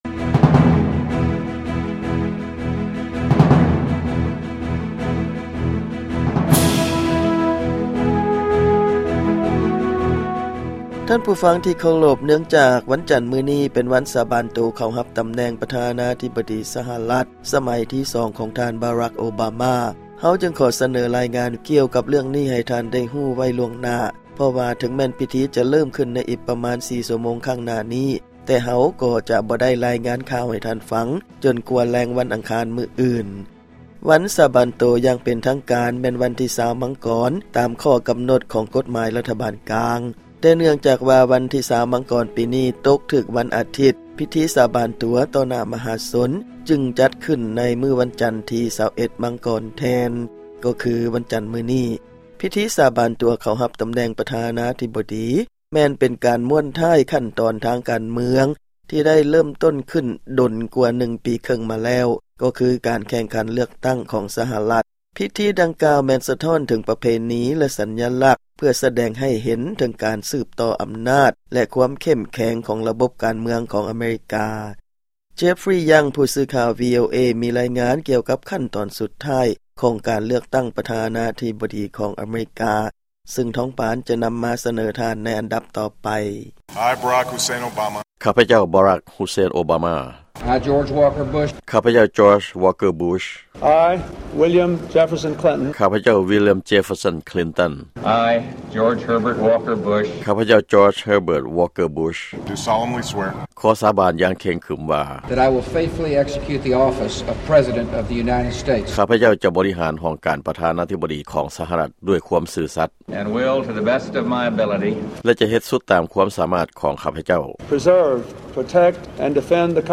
ຟັງລາຍງານພິທີການສາບານຕົວປະທານາທິບໍດີ ສຫລ